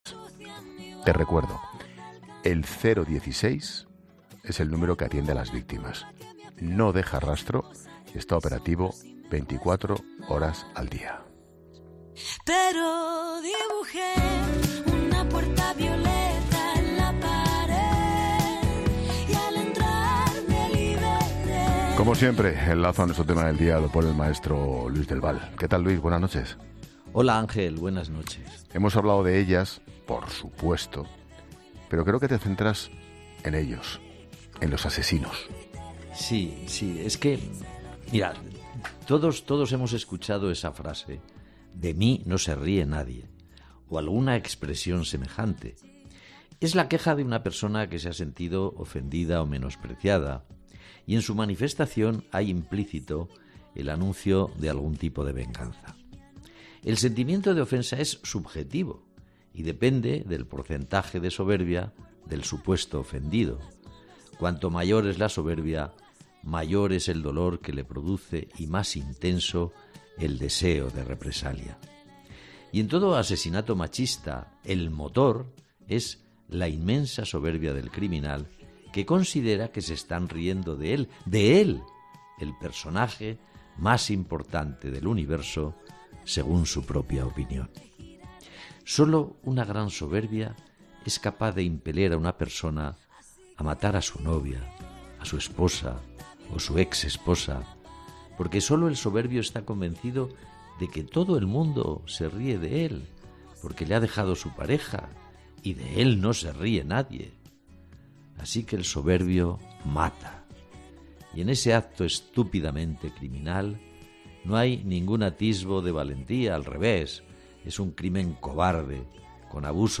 El periodista Luis del Val ha ofrecido su análisis sobre la violencia machista en el programa 'La Linterna' de la COPE, donde, a petición de Ángel Expósito, ha puesto el foco en la figura del asesino.